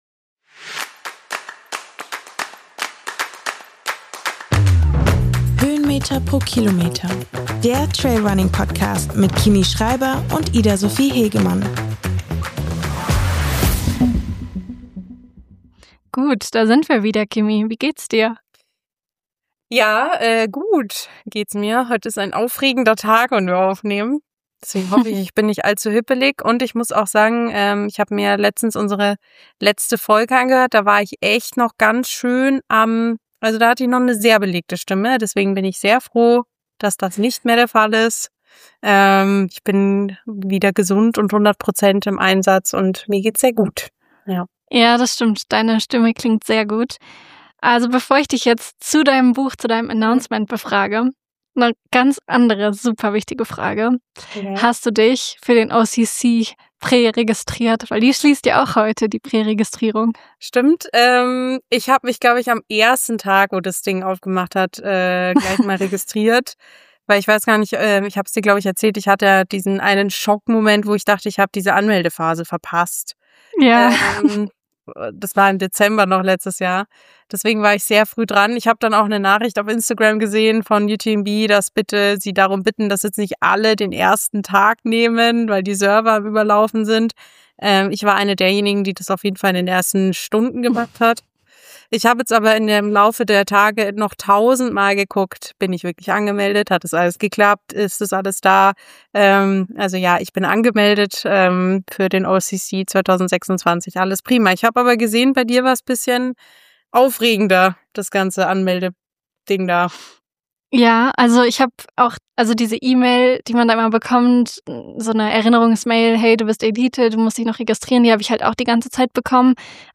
Nächstes Mal sind wir wieder technisch einwandfrei, versprochen.